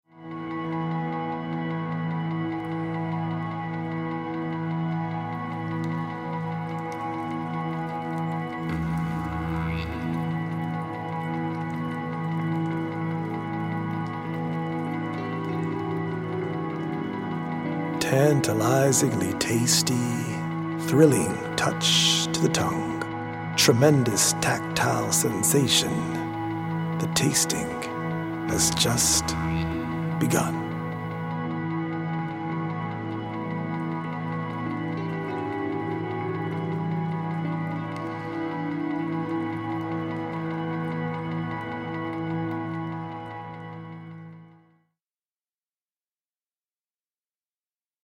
audio-visual poetic journey
healing Solfeggio frequency music